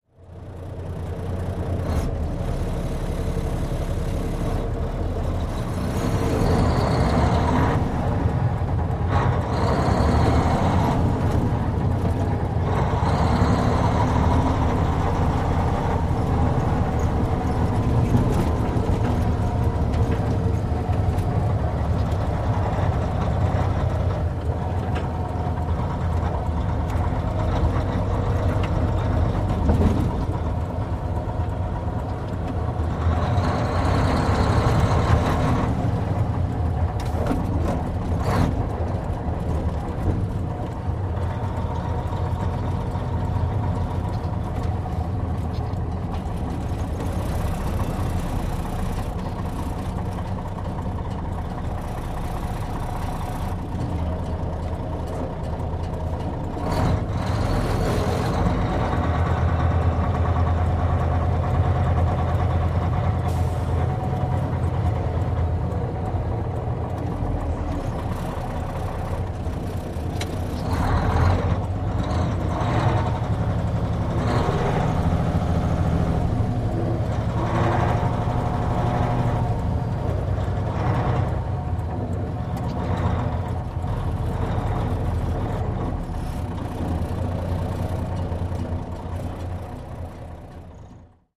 tr_macktruck_driving_01_hpx
Mack truck interior point of view of driving and shifting gears. Vehicles, Truck Engine, Motor